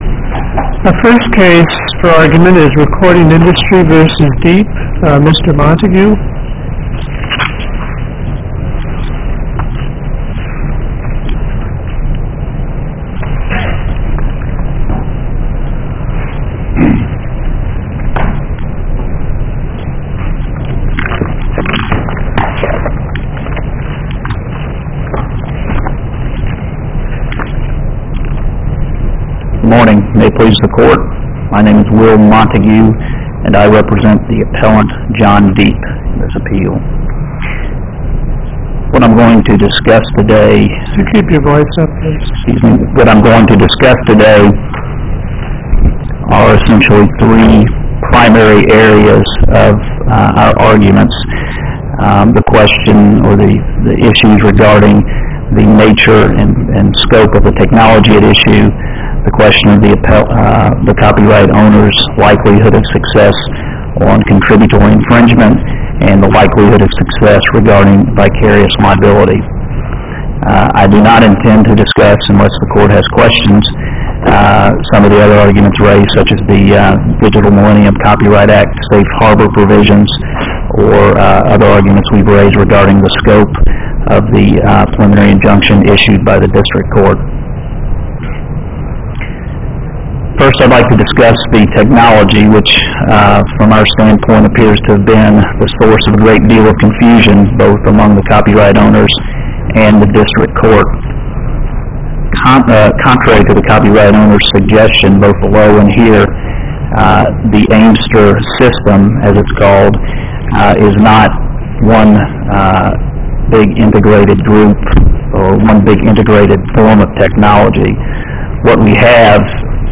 MP3 of Oral argument
7th_oral_argument.mp3